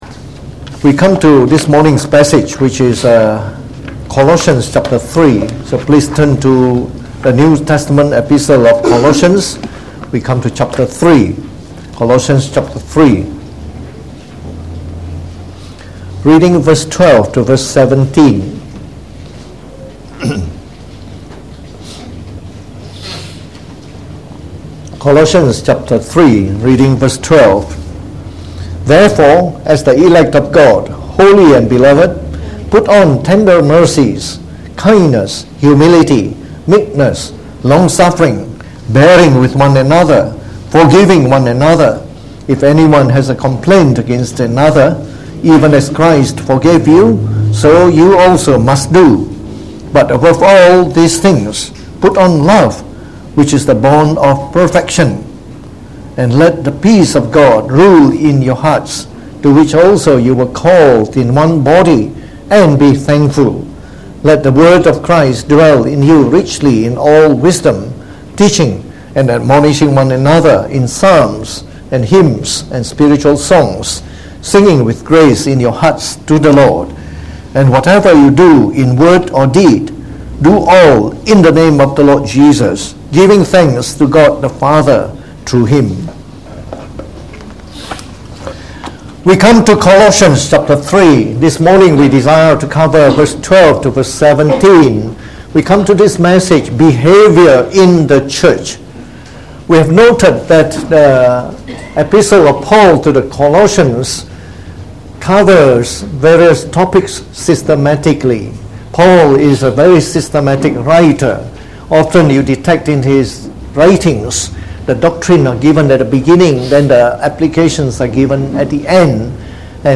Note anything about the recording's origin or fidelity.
Preached on the 14th of April 2019.